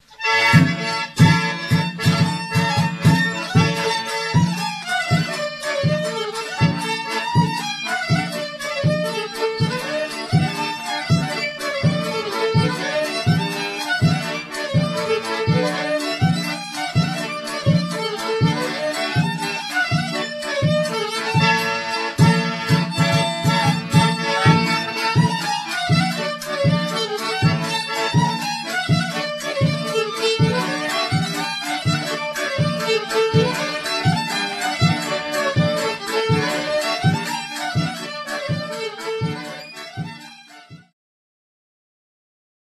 Oberek (Przybyszew, 1987)
harmonia 3-rzędowa, 24-basowa
skrzypce
baraban z talerzem